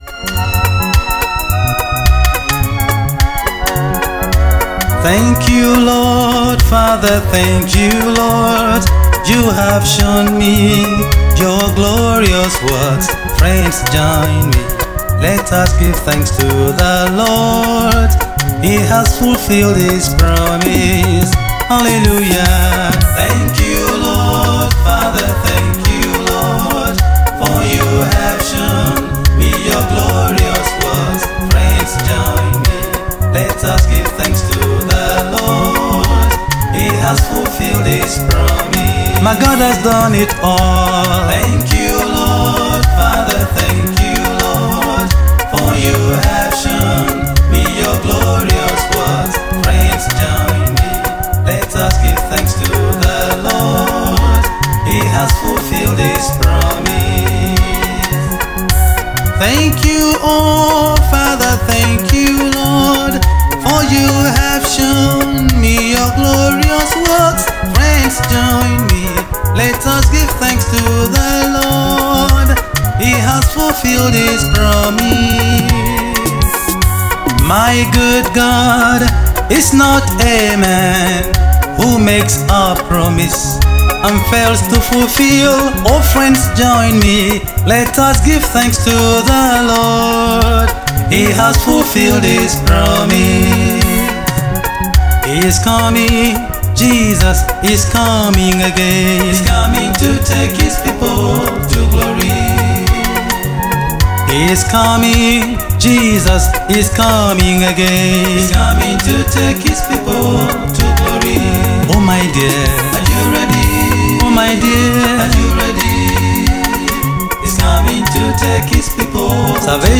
January 20, 2025 Publisher 01 Gospel 0